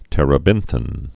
(tĕrə-bĭnthĭn, -thīn)